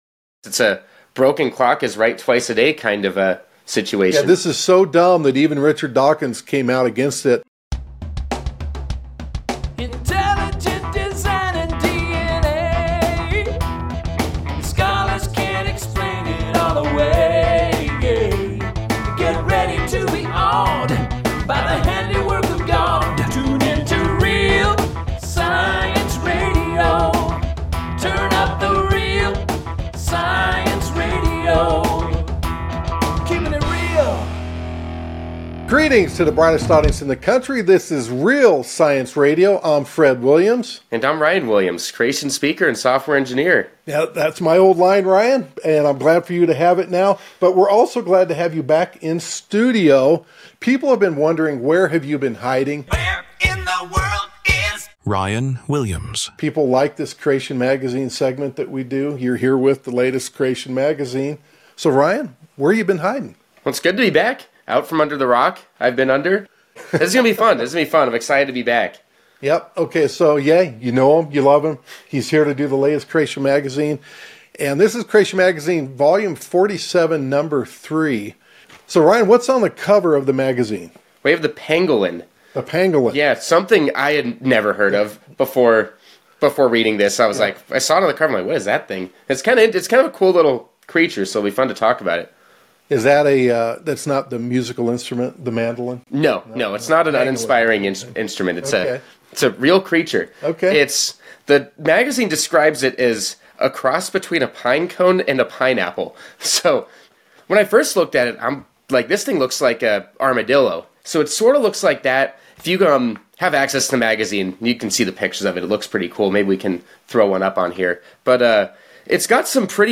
Daily conservative talk show hosted by American's most popular, self-proclaimed right-wing, religious fanatic.